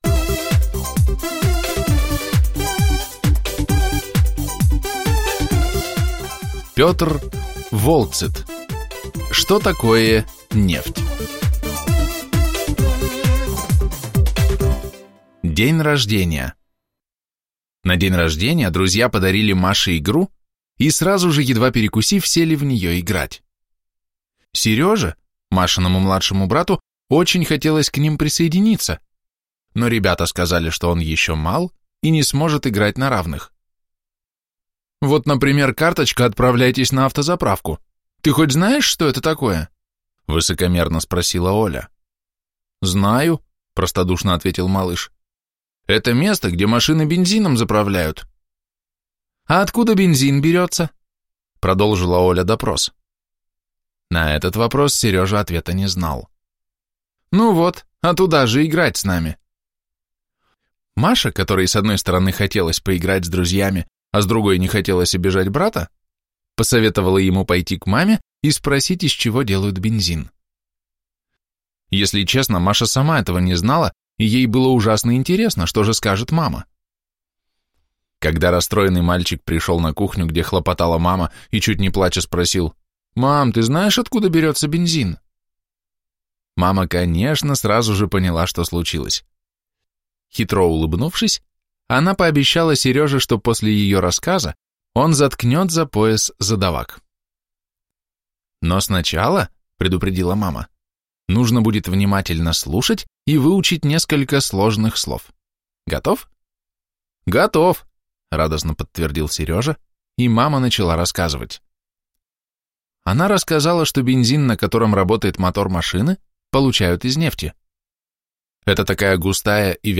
Аудиокнига Что такое нефть?